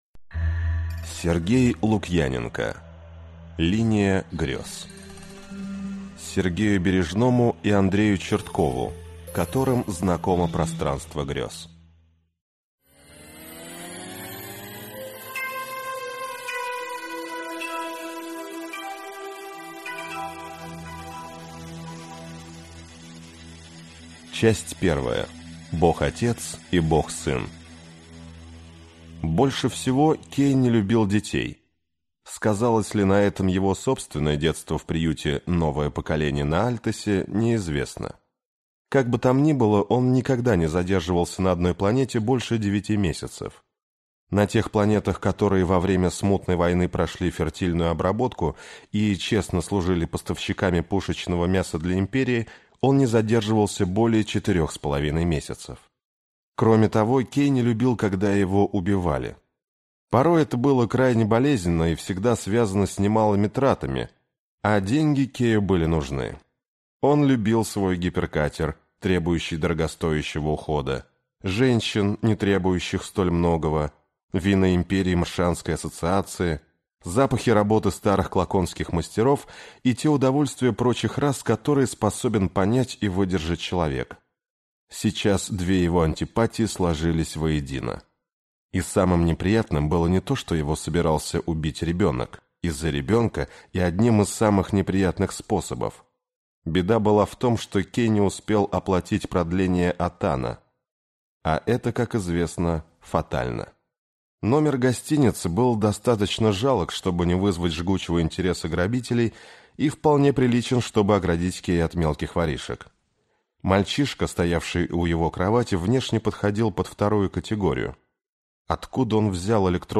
Аудиокнига Линия Грез | Библиотека аудиокниг